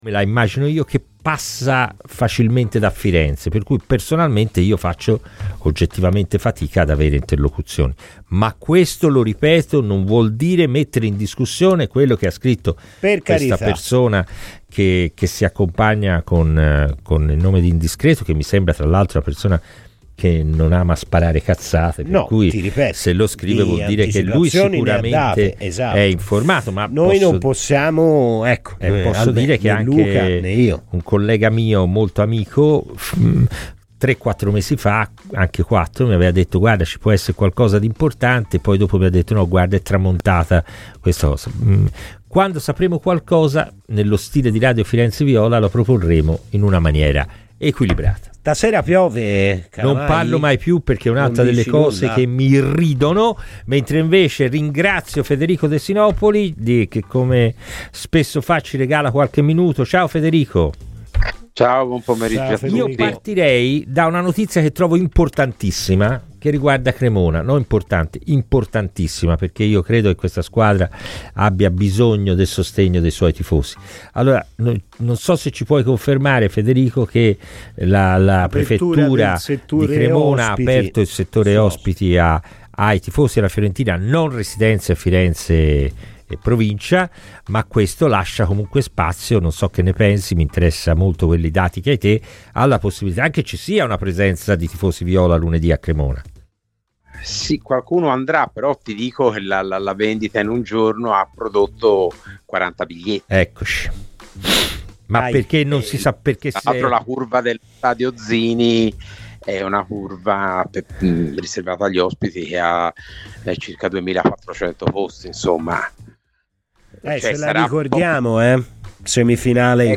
Ascolta il Podcast per l'intevista completa